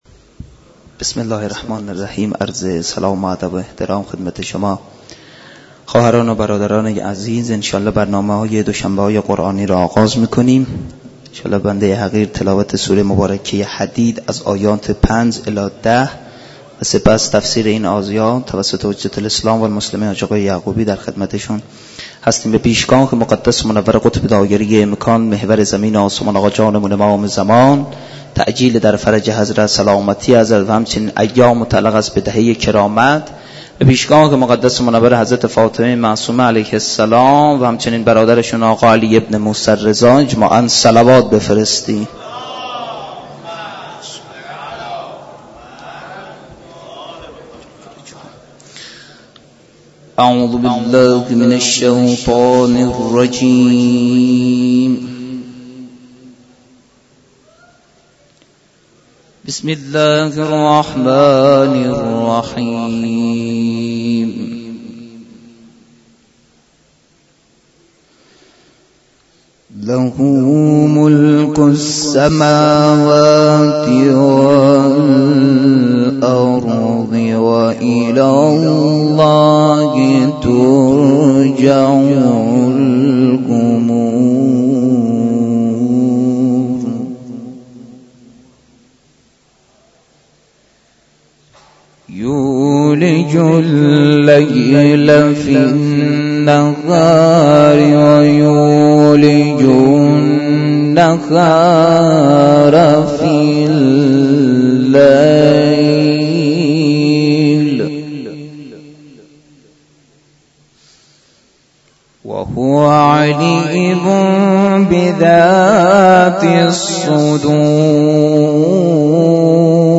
مراسم معنوی دوشنبه های قرآنی در مسجد دانشگاه کاشان